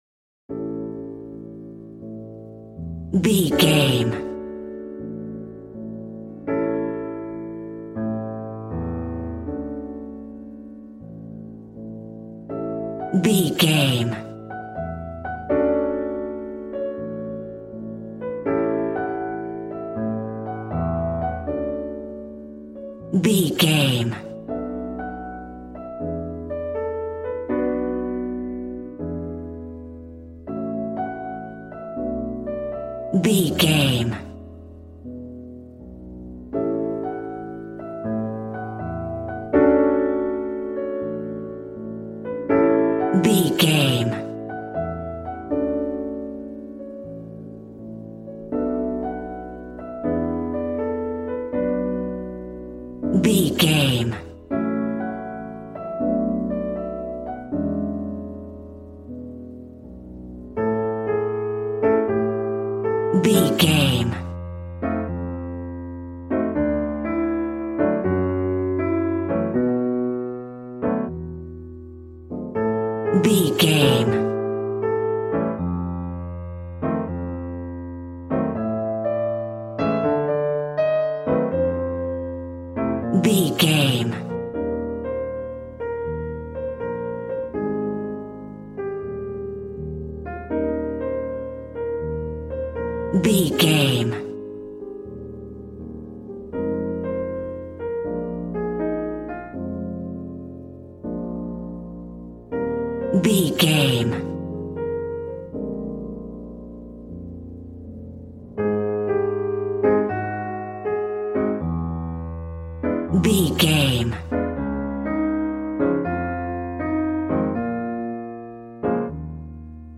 Aeolian/Minor
smooth
piano
drums